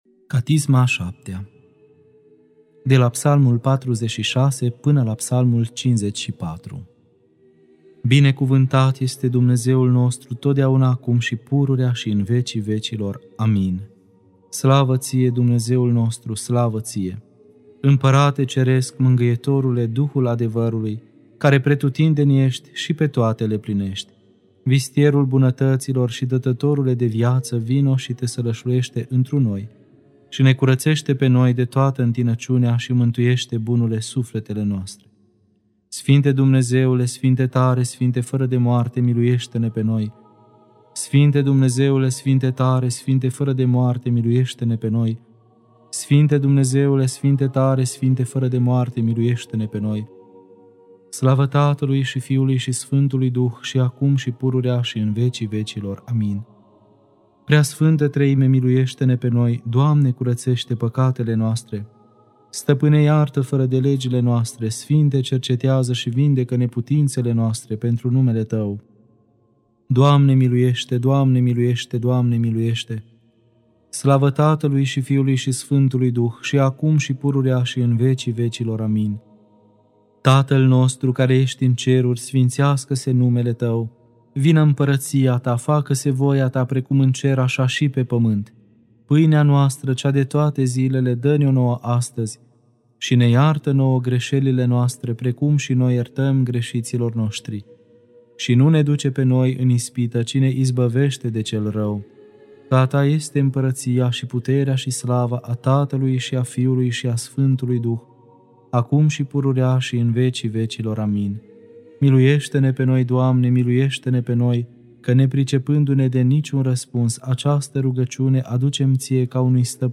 Catisma a VII-a (Psalmii 46-54) Lectura